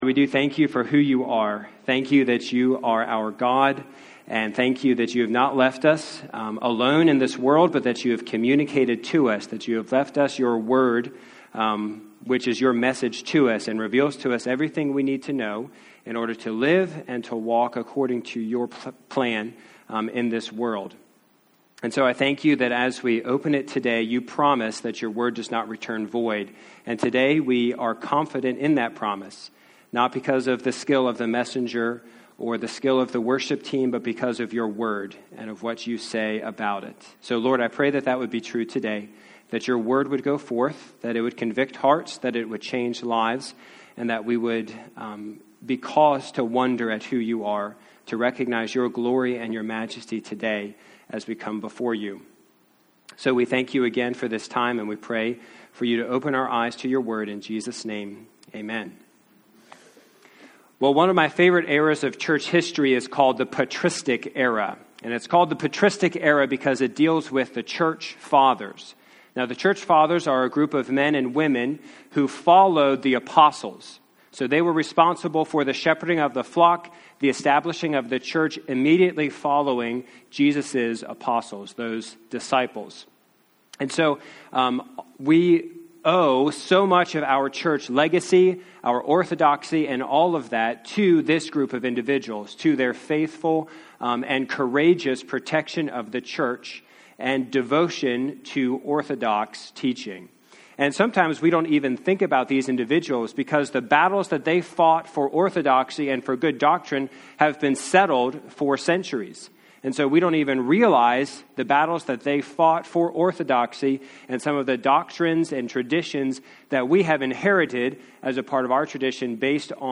The Gospel of Matthew: The Servant King - Church of the Living Christ